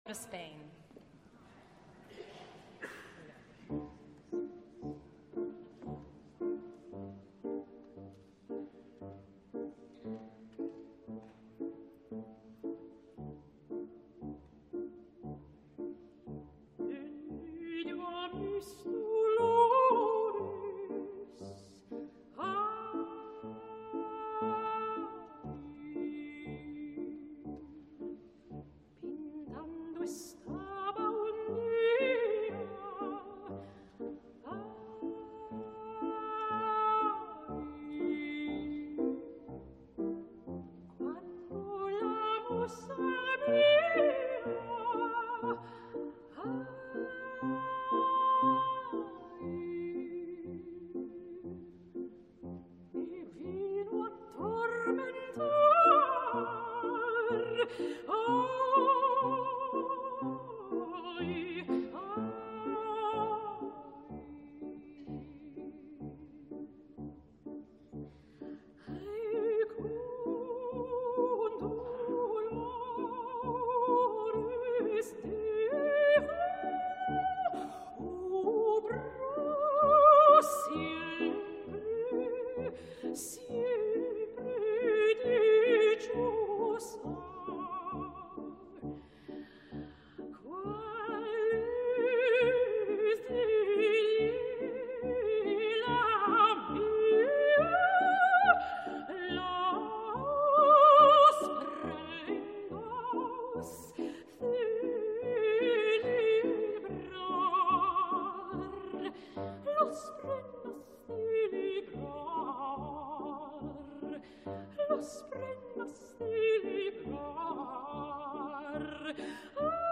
Hi ha en qualsevol cas, una zona aguda massa tibada i tremolosa, motivada per el vibrato que en aquests moments més tensos fa que la subtil i delicada musicalitat i l’enlluernador virtuosisme, s’emmascarin amb puntuals moments que en ca`cas desestabilitzen a una cantant extraordinària.
Joyce DiDonato, mezzosoprano
piano
Stern Auditorium/Perelman Stage at Carnegie Hall de 4 novembre de 2014
I ara escoltem com l’acaba, amb les dues propines, la virtuosística i espectacular “canzonetta spagnuola” de Rossini i l’entranyable “non ti scordar di me” de De Curtis.